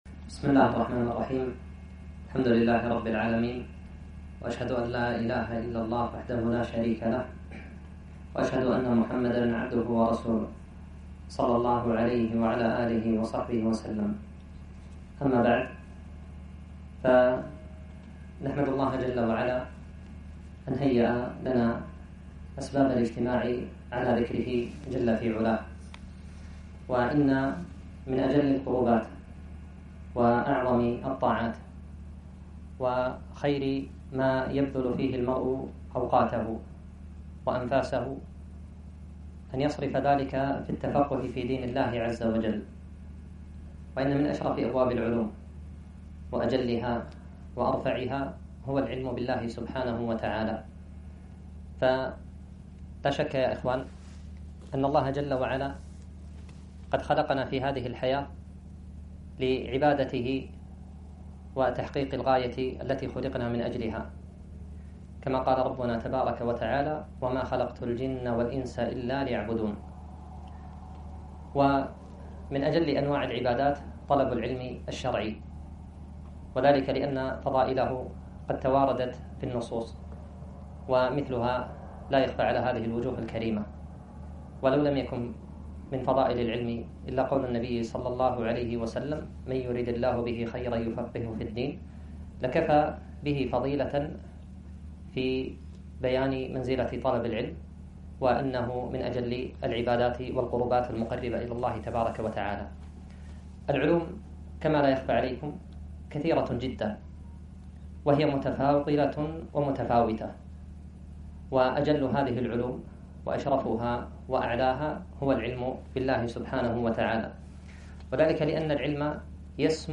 محاضرة - معالم في دراسة أسماء الله جل وعلا الحسنى